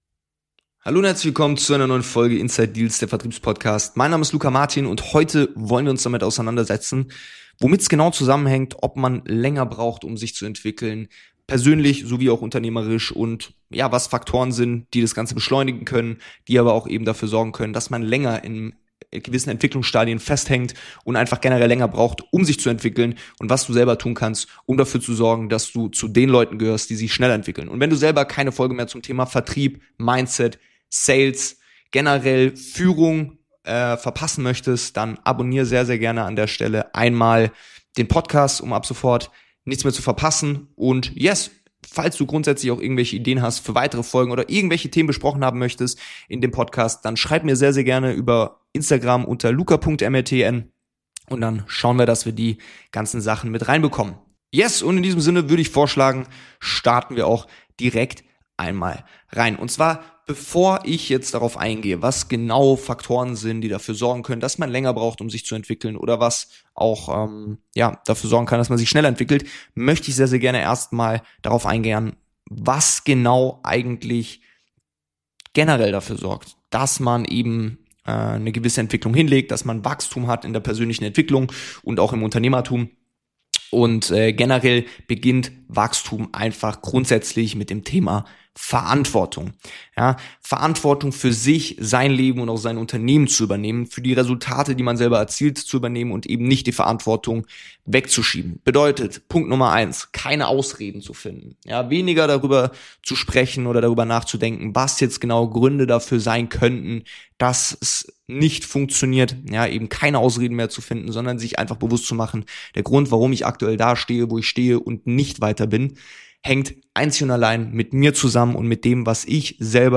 Eine ruhige, reflektierte Folge für Unternehmer und Vertriebler, die sich bewusst weiterentwickeln wollen.